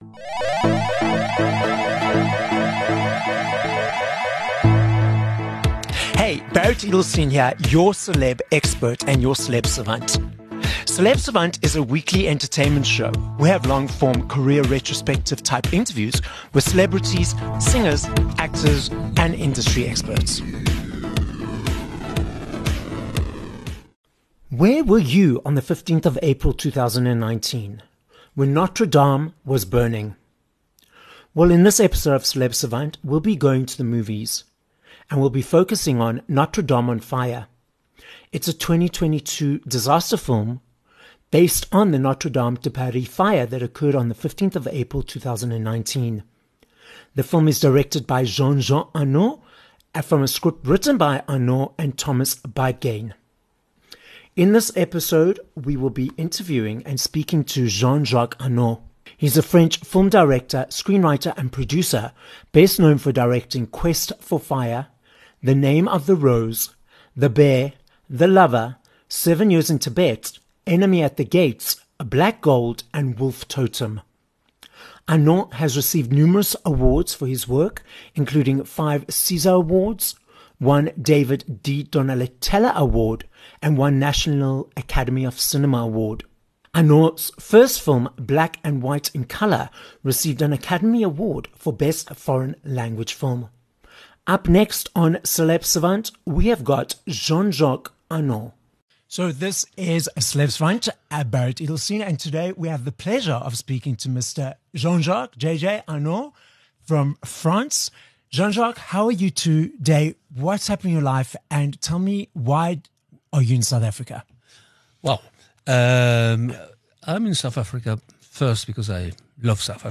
9 Nov Interview with Jean-Jacques Annaud (Director of Notre Dame on Fire)